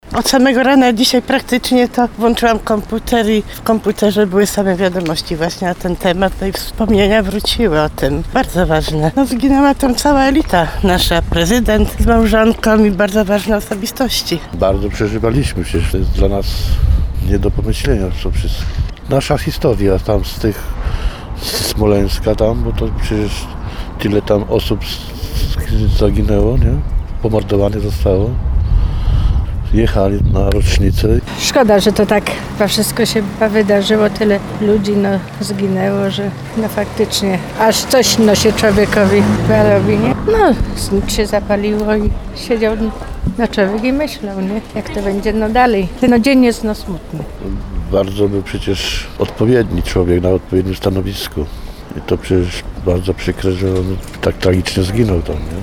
Dziś 4. rocznica katastrofy pod Smoleńskiem, w której zginął Prezydent Lech Kaczyński, Pierwsza Dama RP i 94 inne osoby. Tragedie wspominają mieszkańcy Żnina.